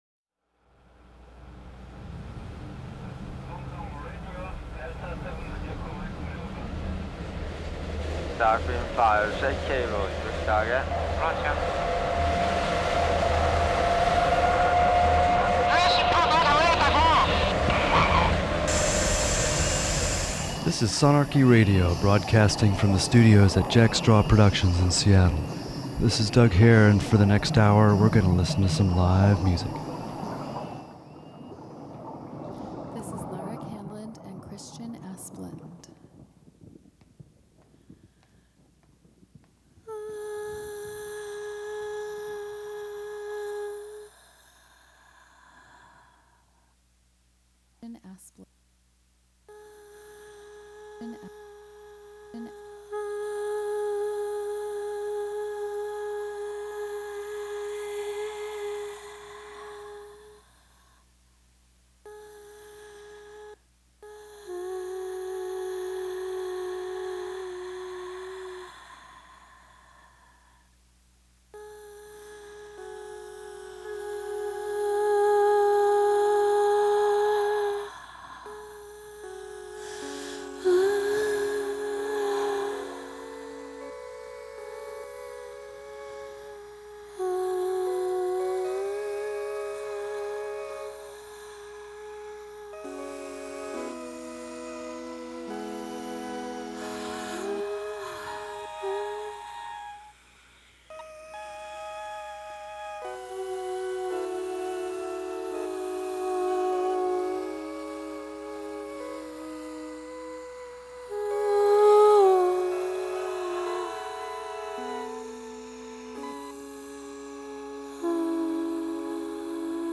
New jazz music
drums
tenor sax
piano
bass